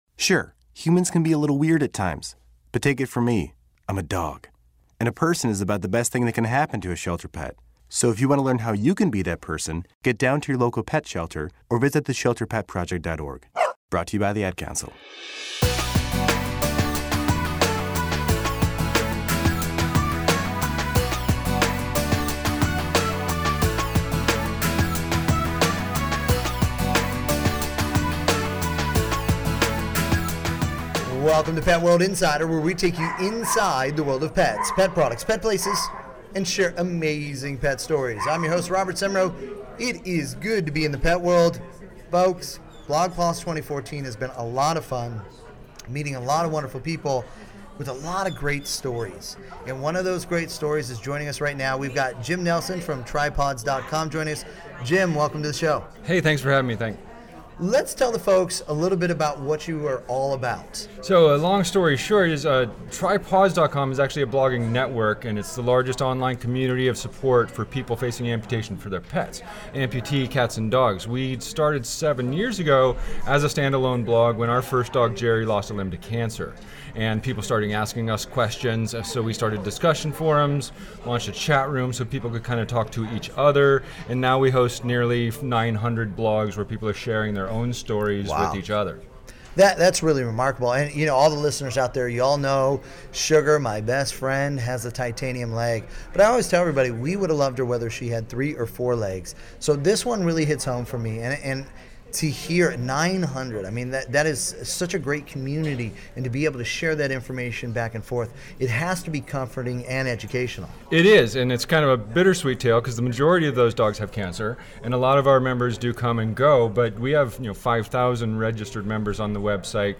On this BlogPaws 2014 Conference Pet World Insider Radio Segment
Enjoy this Pet World Radio Segment in case a station near you does not currently carry Pet World Radio!